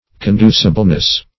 Conducibleness \Con*du"ci*ble*ness\, n.